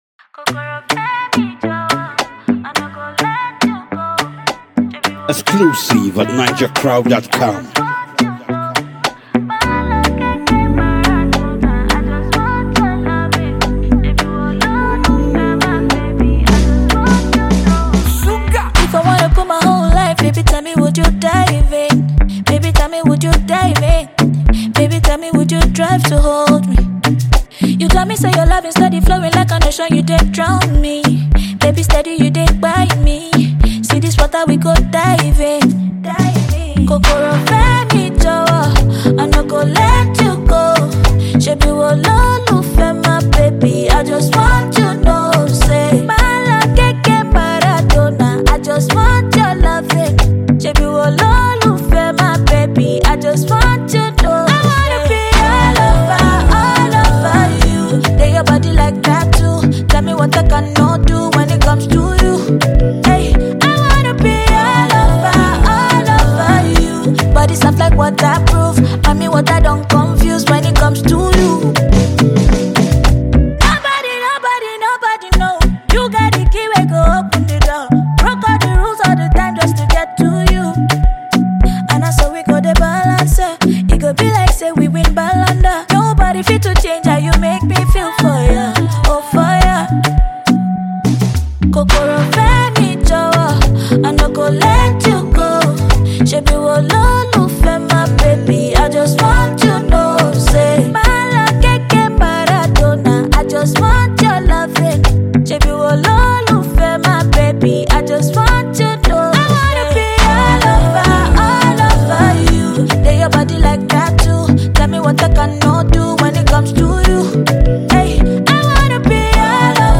female singer